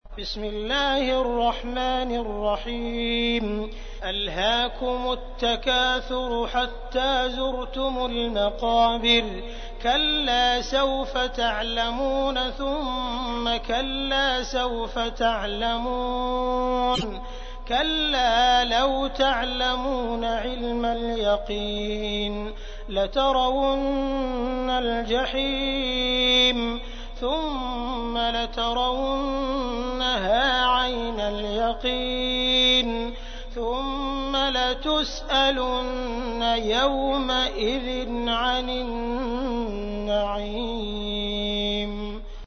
تحميل : 102. سورة التكاثر / القارئ عبد الرحمن السديس / القرآن الكريم / موقع يا حسين